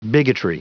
Prononciation du mot bigotry en anglais (fichier audio)
Prononciation du mot : bigotry